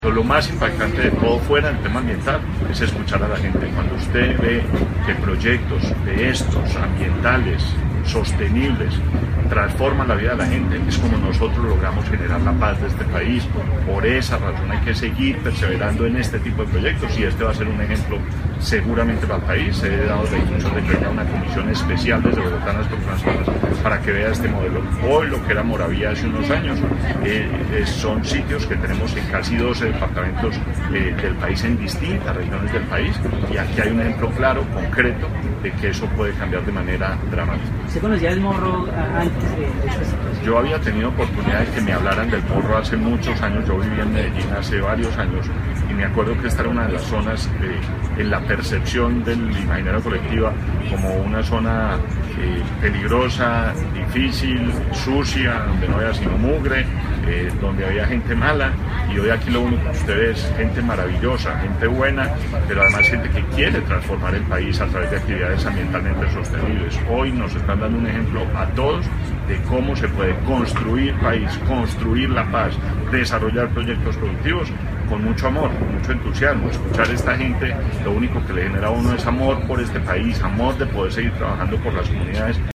Declaraciones del Ministro de Ambiente y Desarrollo Sostenible, Gabriel Vallejo audio